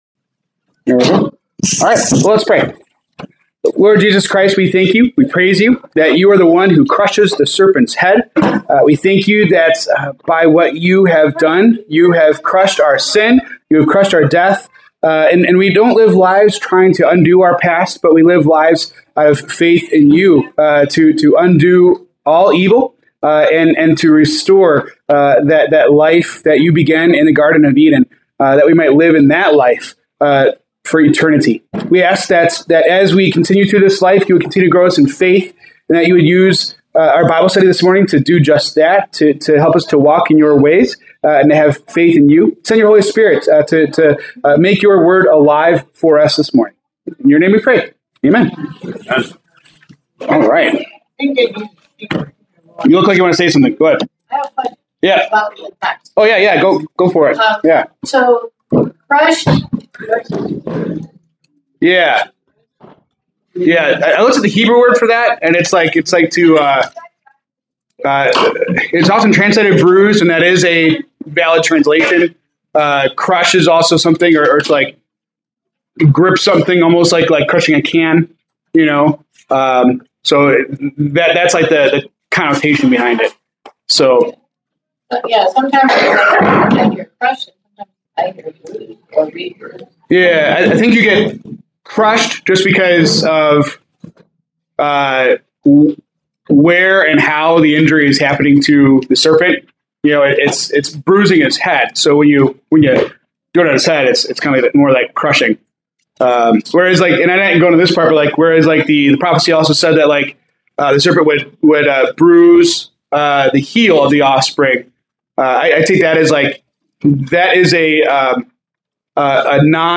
February 22, 2026 Bible Study